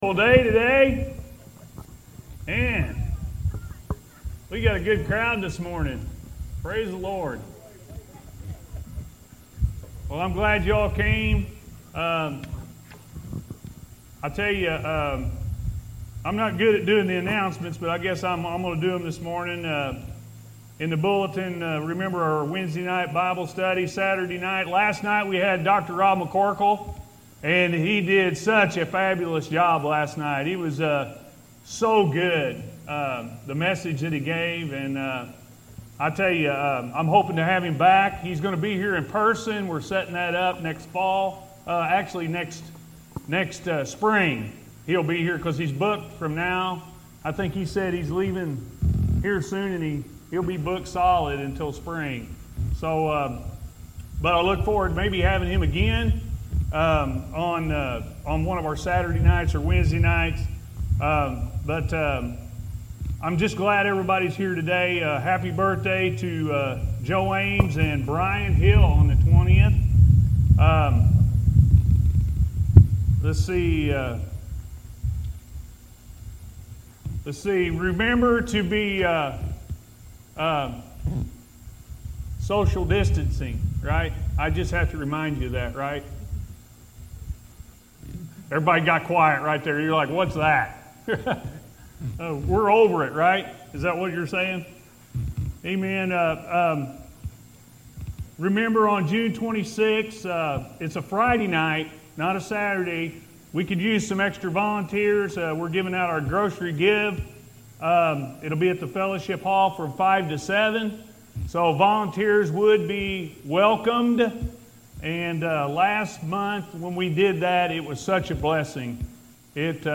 God Loves You-A.M. Service